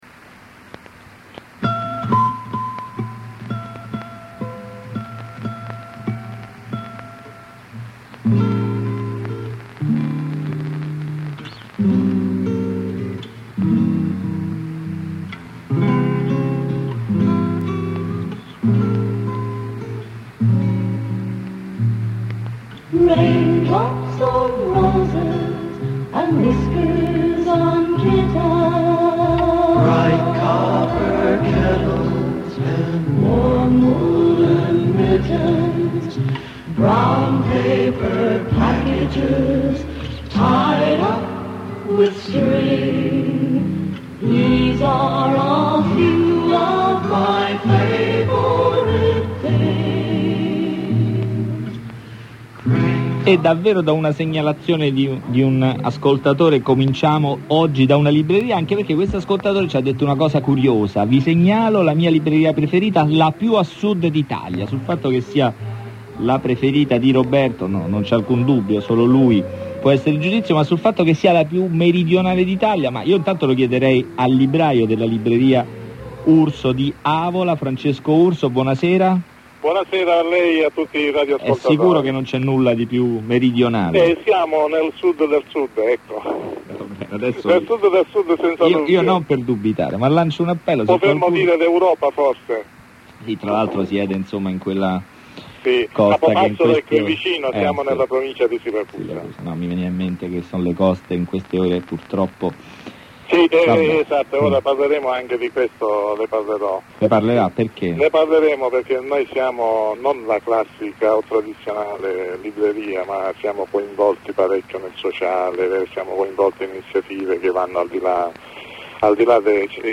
I redattori si mettono in collegamento
con ascoltatori-lettori e librai